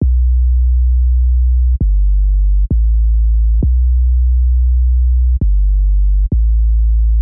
描述：环境模式由采样和处理的扩展小号技术创建。
标签： 大气压 实验 扩展的技术 嘶嘶声 处理 小号
声道立体声